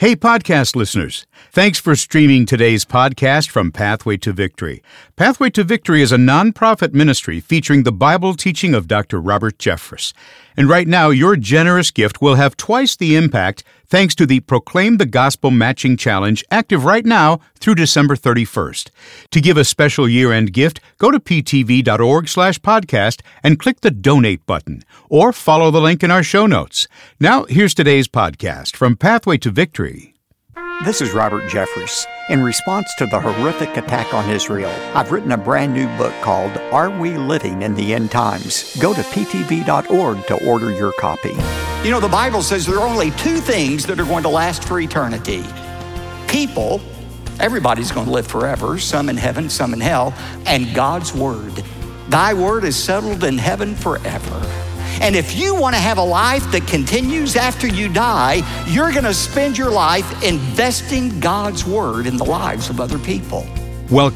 Israel End Times God's Word Robert Jeffress Bible Teaching Christian Ministry discussion